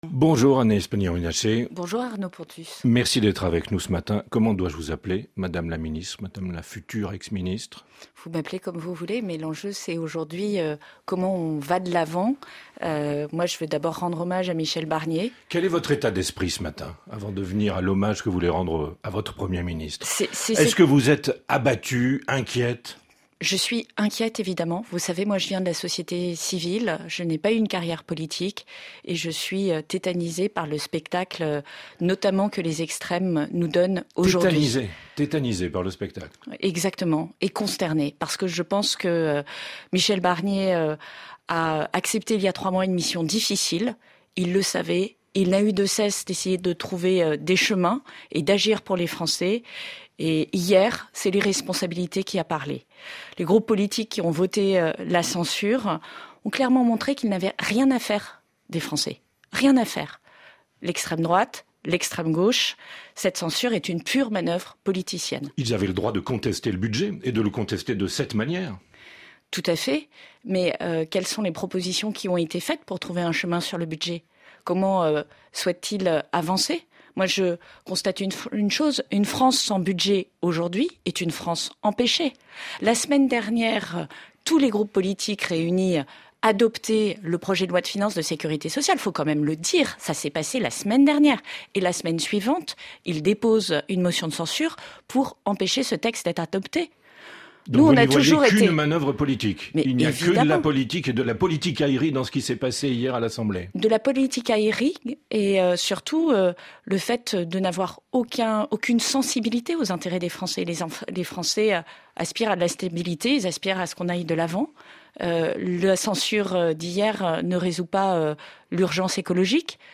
Agnès Pannier-Runacher, ministre française de la Transition écologique, de l’Énergie, du Climat et de la Prévention des risques est l'invitée de RFI ce 5 décembre.
Pour le prochain gouvernement, elle appelle à un « rassemblement de toutes les forces républicaines qui inclut les socialistes ». Entretien.